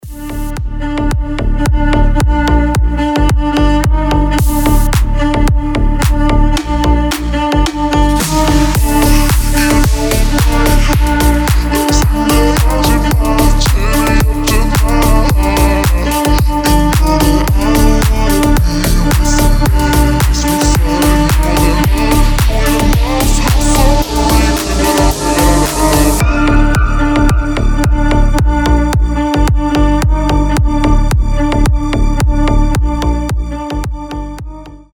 • Качество: 320, Stereo
deep house
EDM
скрипка
басы
Крутой дип с грувом